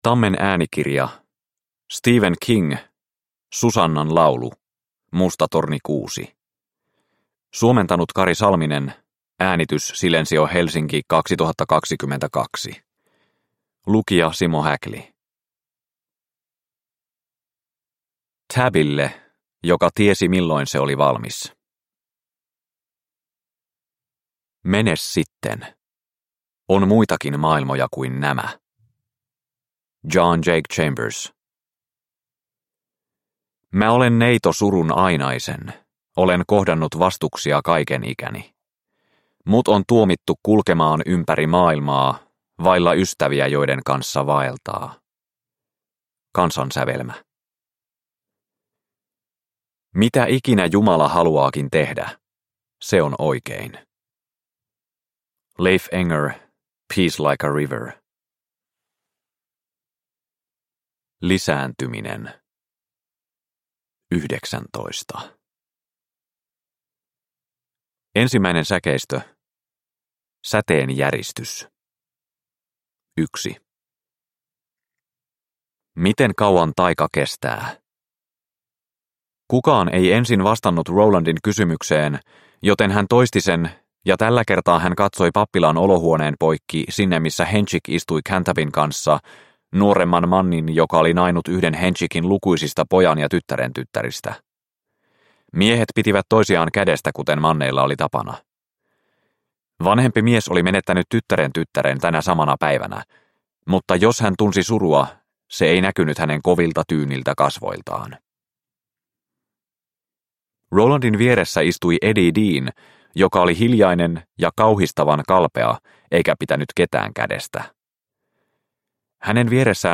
Susannan laulu – Ljudbok – Laddas ner